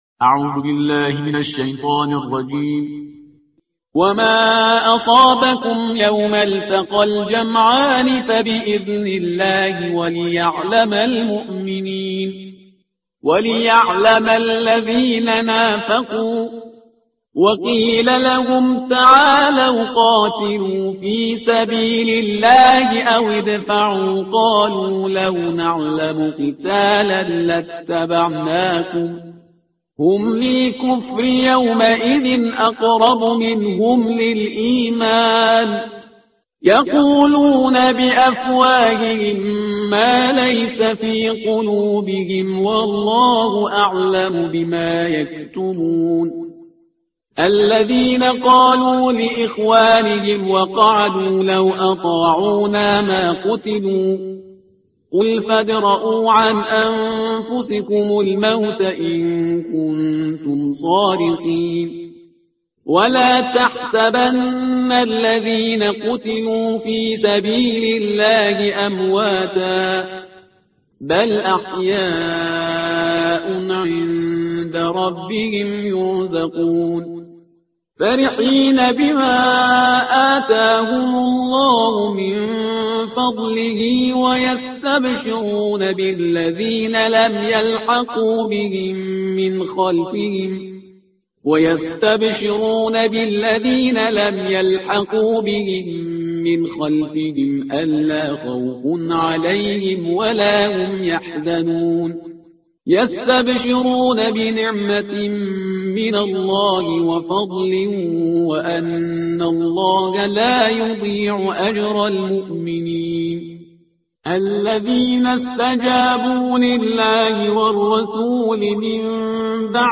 ترتیل صفحه 72 قرآن – جزء چهارم